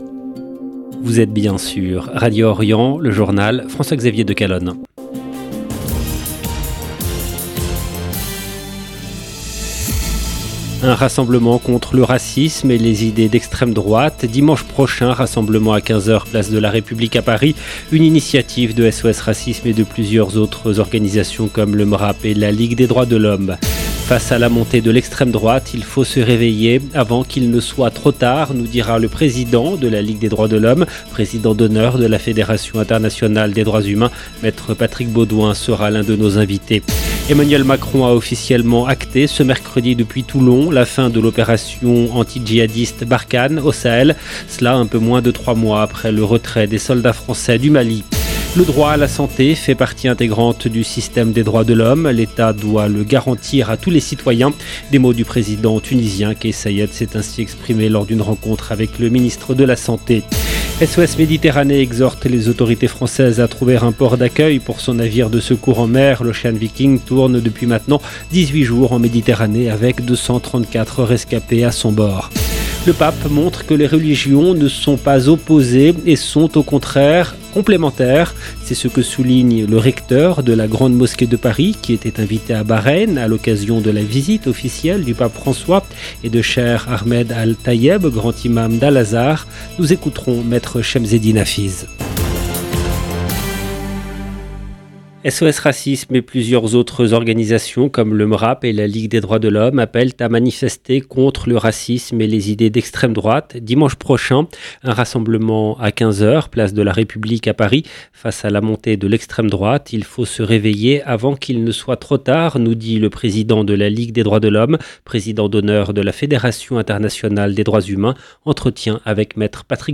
EDITION DU JOURNAL DU SOIR EN LANGUE FRANCAISE DU 9/11/2022